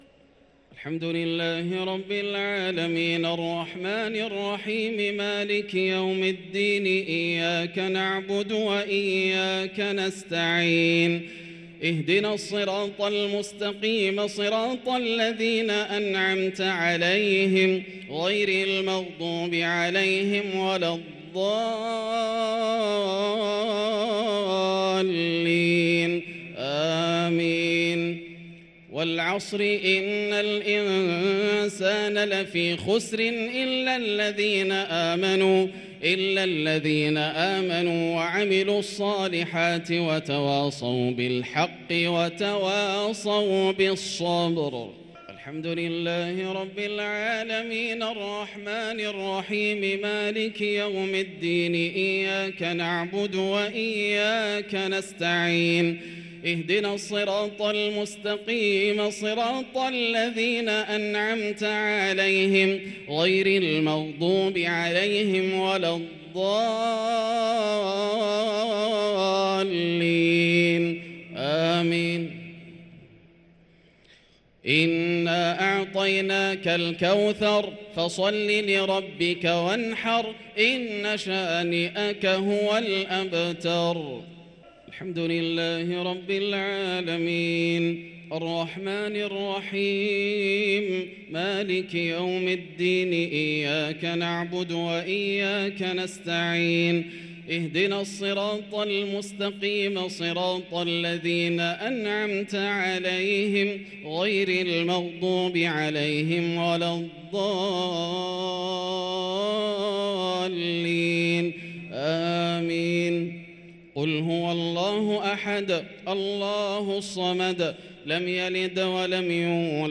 الشفع و الوتر ليلة 6 رمضان 1444هـ | Witr 6 st night Ramadan 1444H > تراويح الحرم المكي عام 1444 🕋 > التراويح - تلاوات الحرمين